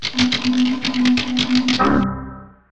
Typewriting Error
Error Keyboard Typewriting Windows sound effect free sound royalty free Memes